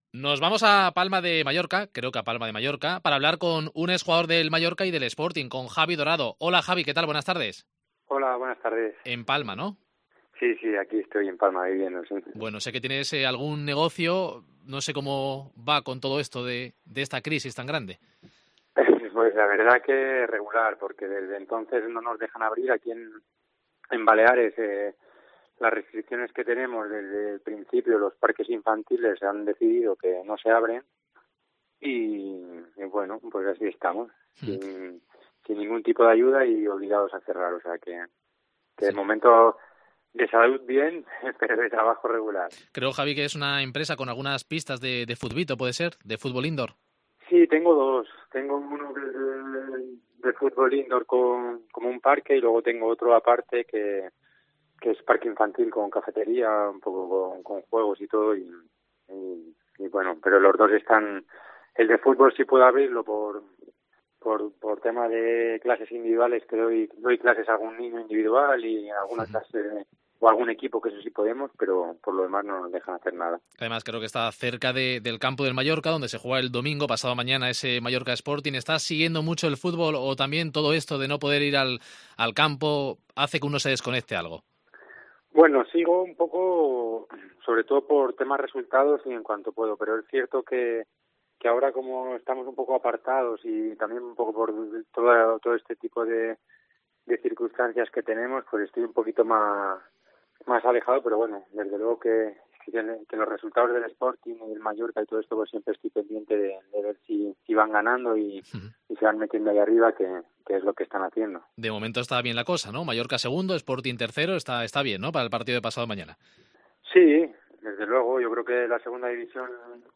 ENTREVISTA EN DCA